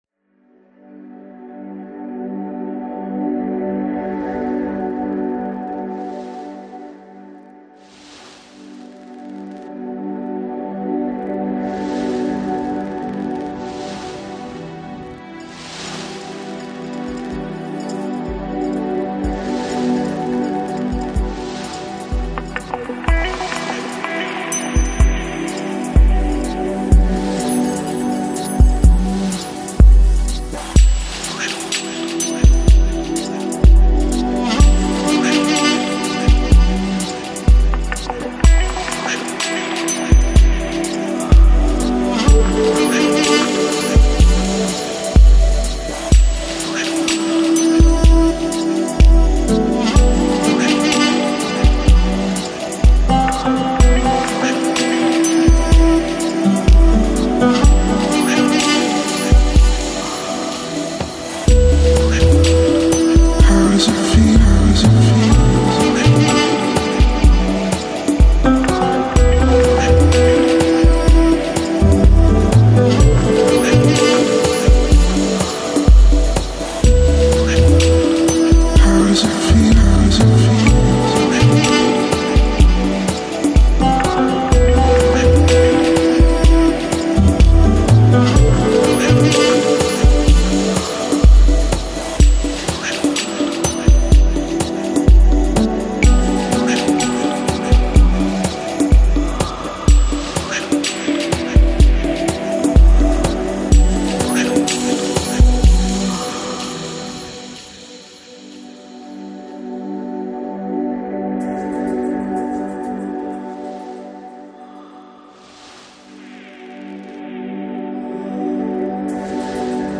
Жанр: Electronic, Lounge, Chill Out